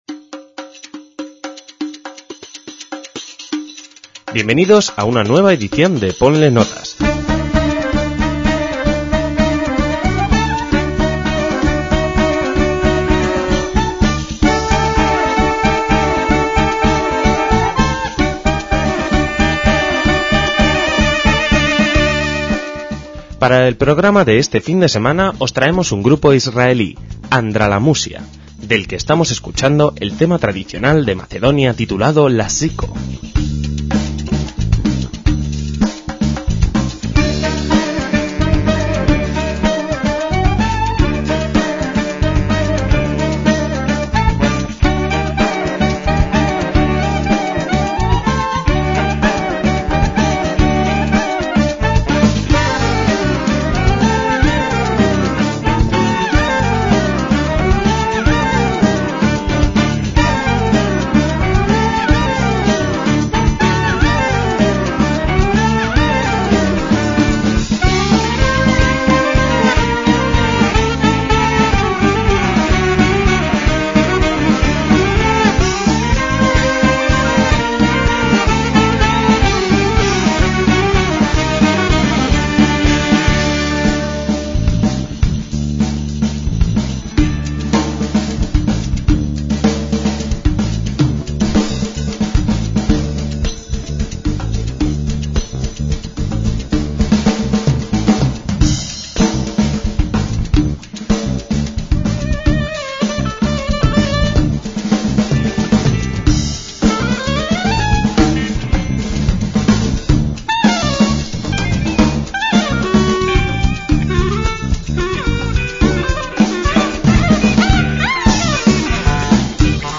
sexteto instrumental israelí
AndraLaMoussia significa caos en hebreo: del mismo modo su música intenta reflejar la diversa realidad cultural en la que vivimos.